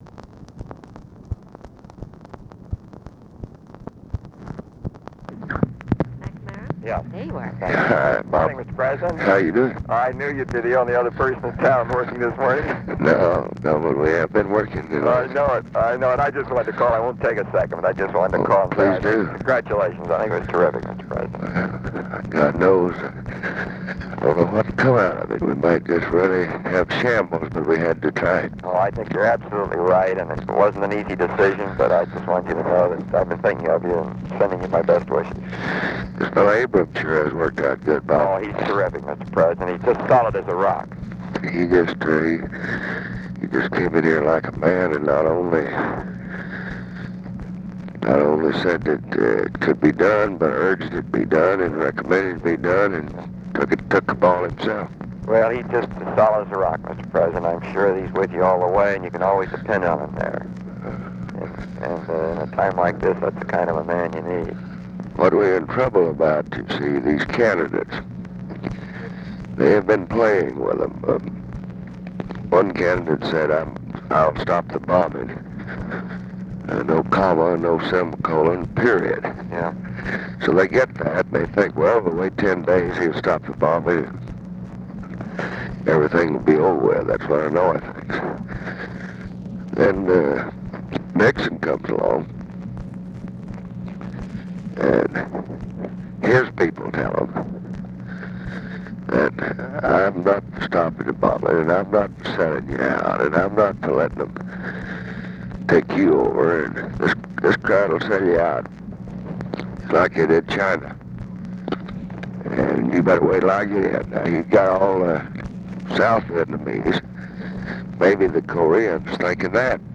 Conversation with ROBERT MCNAMARA, November 1, 1968
Secret White House Tapes